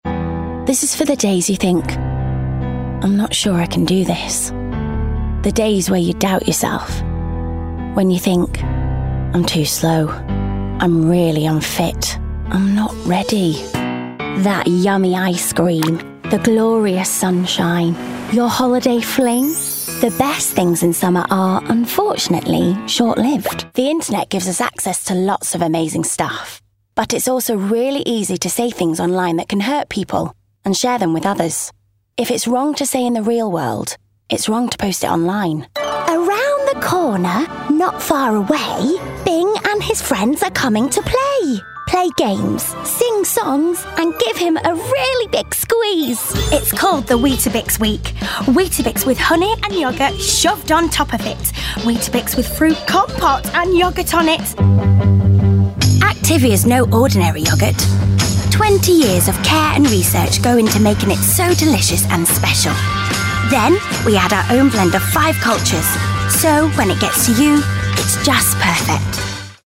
• Female